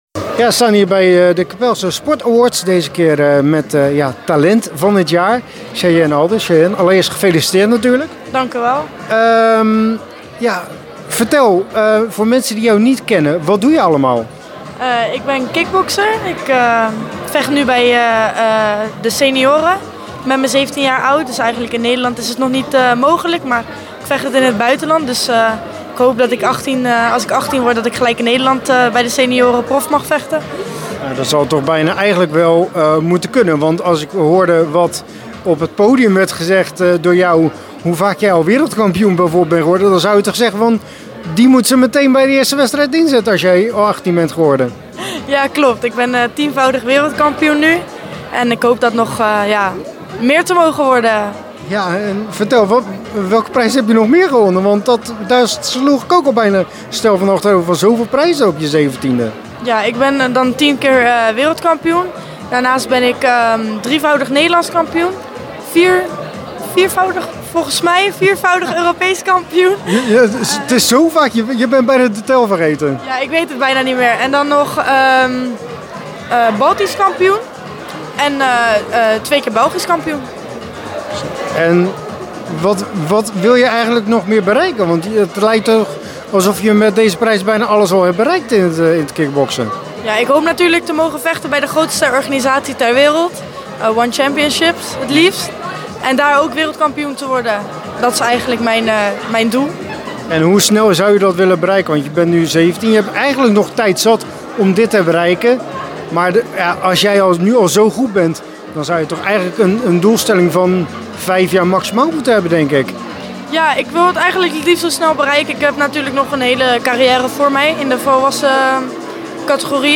Zij werd afgelopen jaar o.a. Wereldkampioen, Europees kampioen en Belgisch Kampioen. In deze podcast een interview met haar.